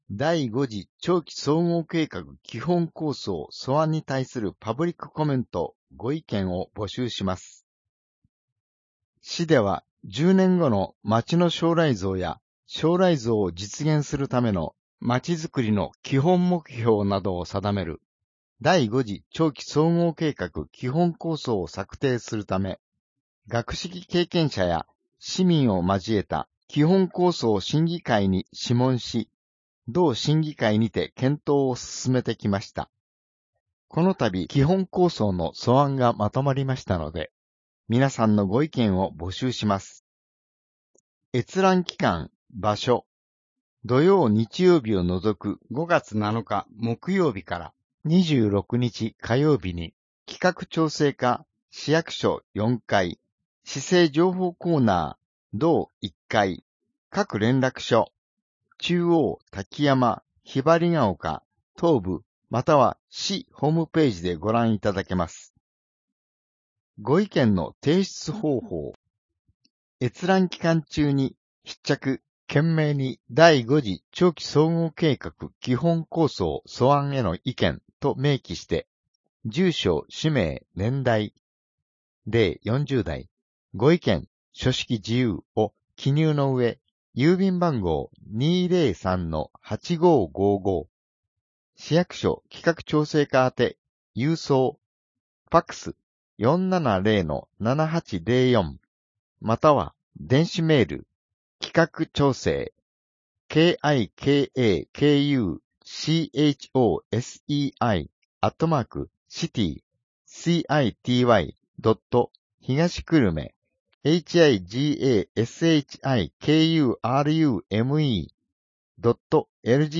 声の広報（令和2年5月1日号）